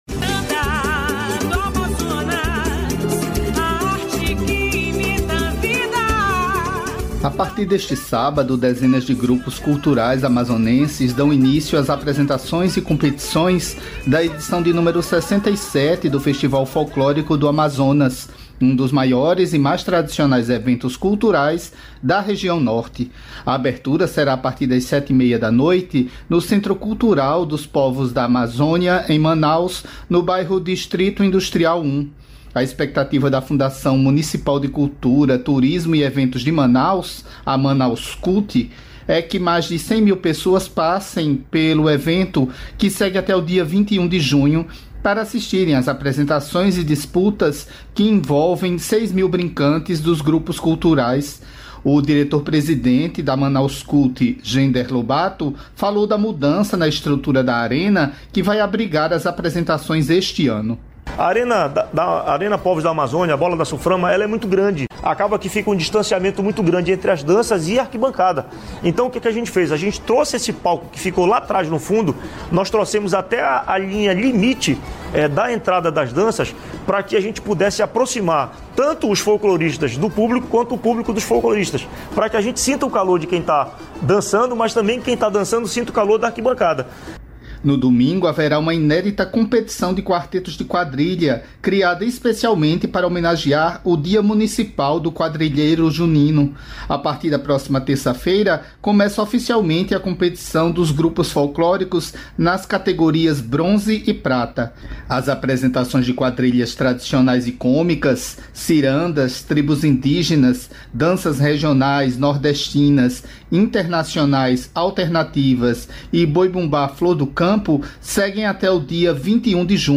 Além do podcast sobre doenças raras, confira entrevista exclusiva com secretaria nacional dos Direitos da Pessoa com Deficiência, Anna Paula Feminella
Nesta terça-feira (28), data em que é celebrado o Dia Mundial das Doenças Raras – oficialmente dia 29, em anos bissextos, a Radioagência Nacional traz uma entrevista com a secretária nacional de Pessoa com Deficiência, Anna Paula Feminella, do Ministério dos Direitos Humanos e Cidadania (MDH).